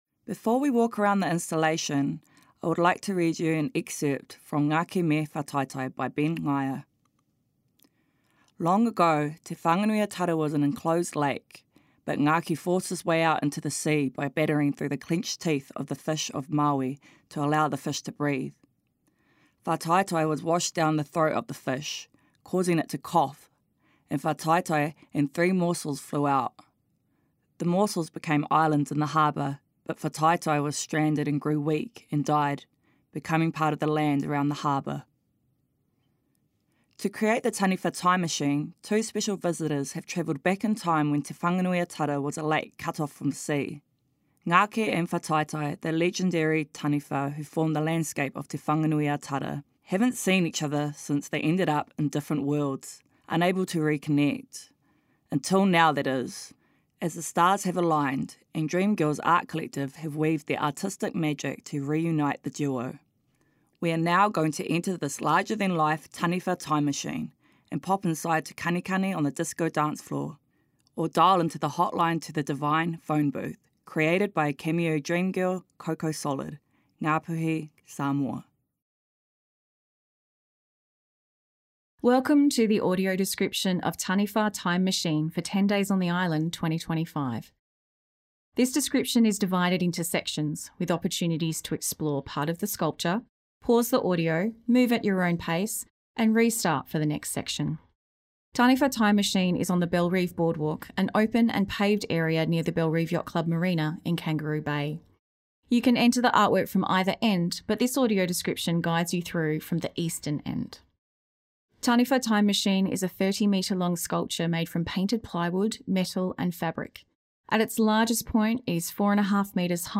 Listen to and download the audio description for Taniwha Time Machine here